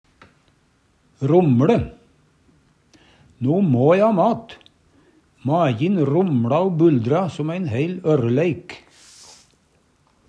romLe - Numedalsmål (en-US)
romLe rumle, buldre, boble, lage rullande lyd Infinitiv Presens Preteritum Perfektum romLe romLa romLa romLa Eksempel på bruk No må e ha mat, majin romLa o buldra som ein heil ørreleik. Hør på dette ordet Ordklasse: Verb Attende til søk